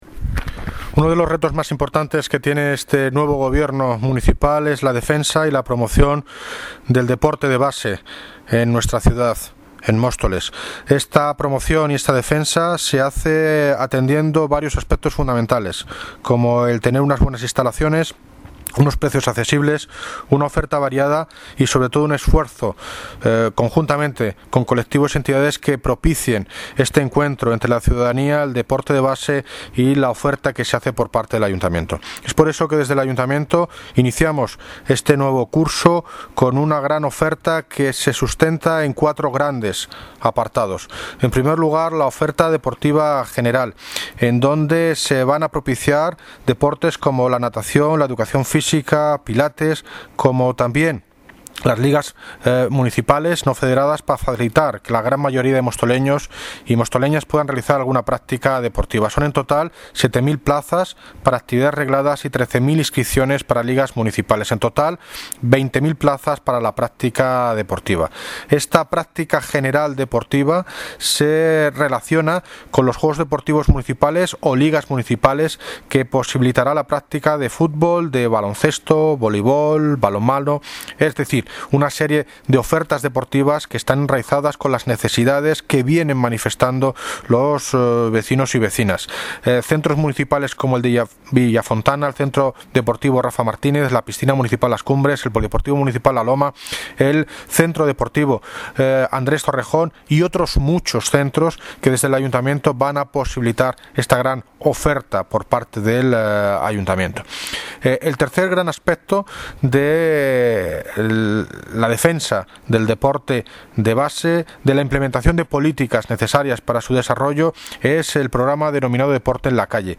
Audio - David Lucas (Alcalde de Móstoles) Presenta programacion actividades deportivas